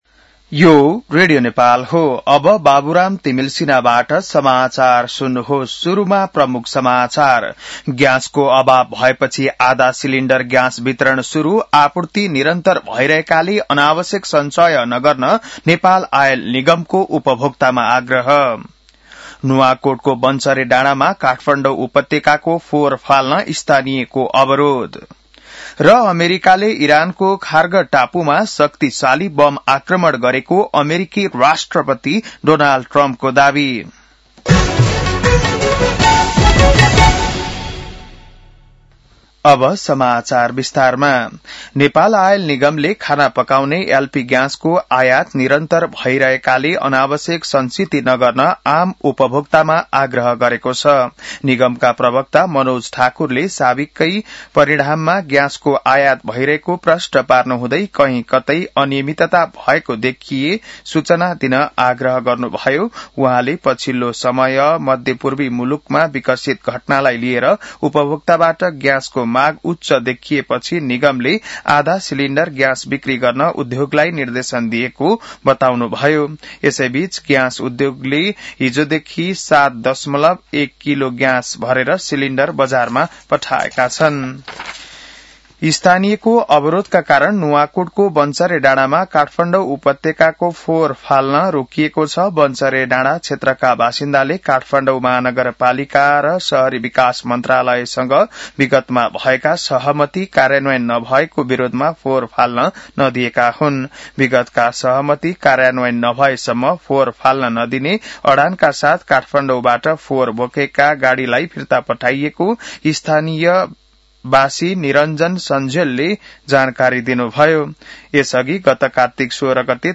बिहान ९ बजेको नेपाली समाचार : ३० फागुन , २०८२